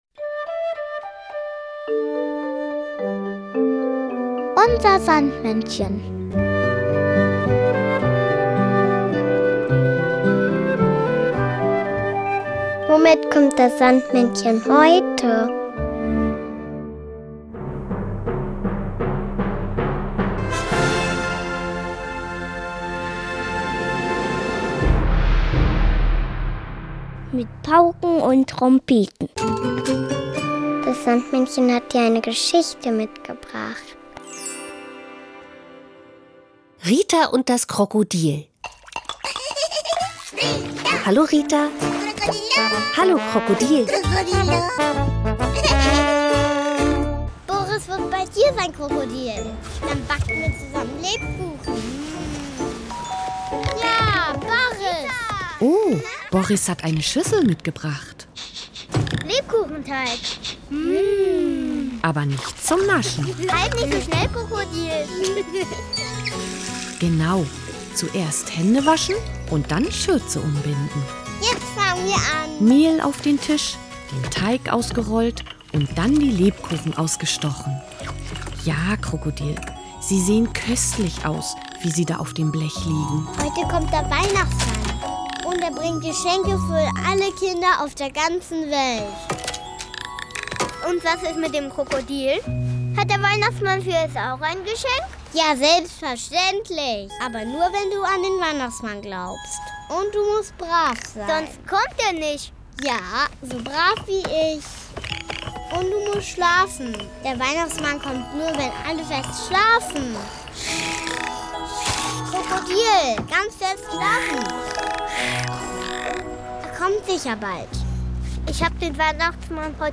Weihnachtslied "Weihnachtsmänner" von Knister.